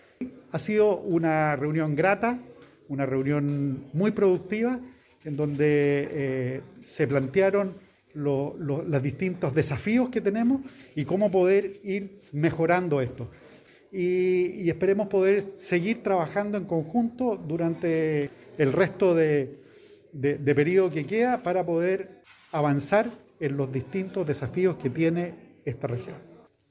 Gobernador Vallespín y Delegado Geisse encabezan reunión de gabinete con seremis Por su parte, el Delegado Presidencial, Carlos Geisse, explicó que esta es la segunda región a nivel de país que se reúne el gabinete con el Gobernador Regional, apuntando que obedece a la importancia de fomentar un trabajo coordinado por el bien de los habitantes de la región.